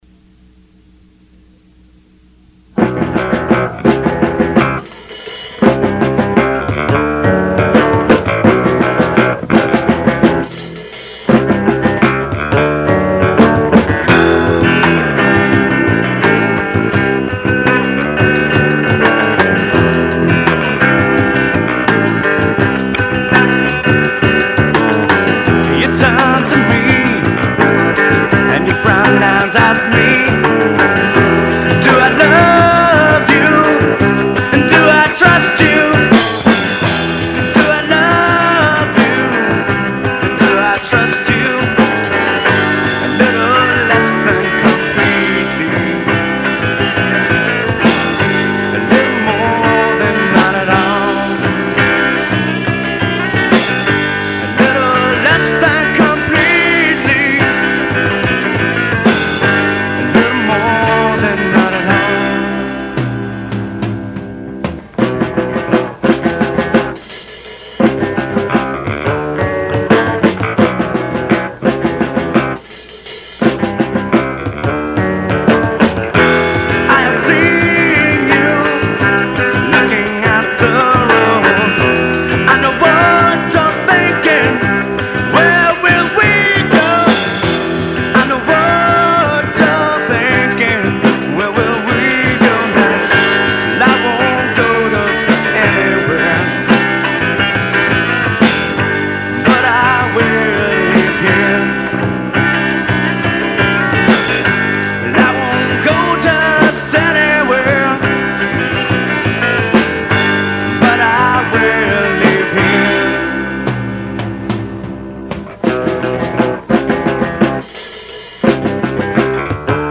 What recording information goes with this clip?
1992-1993 Demo's Recorded at Zombie Birdhouse, Oxford, MS